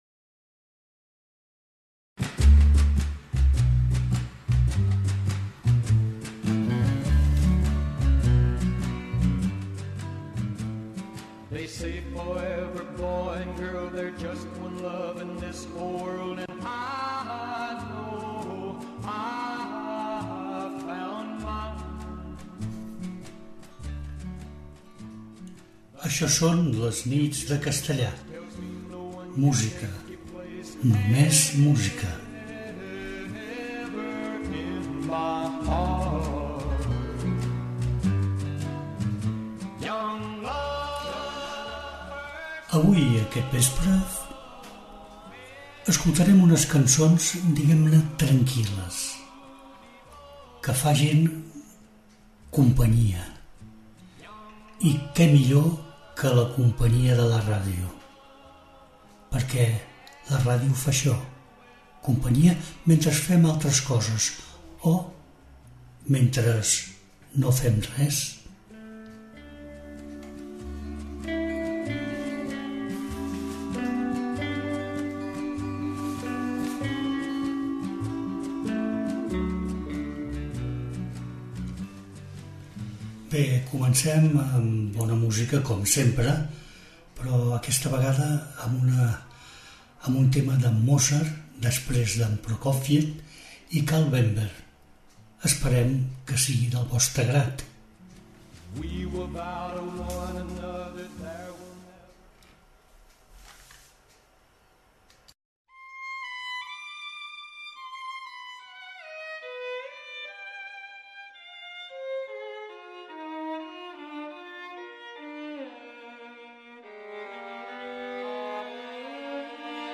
en un to intimista i relaxant.